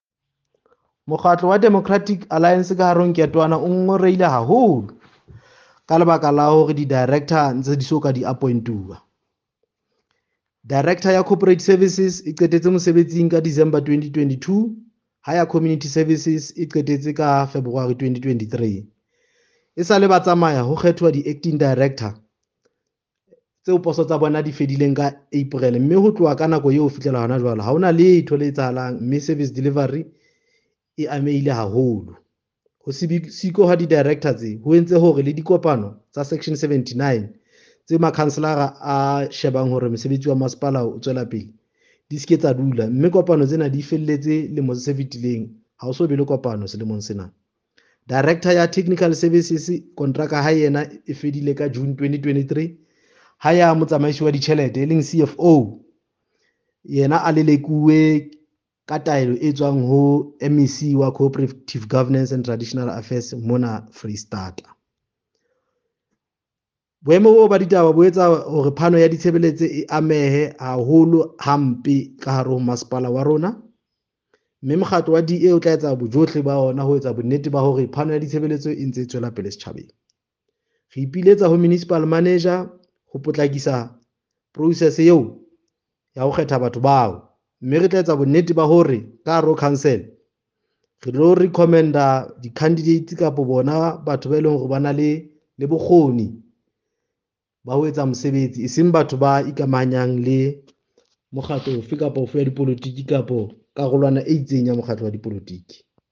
Sesotho soundbites by Cllr Diphapang Mofokeng.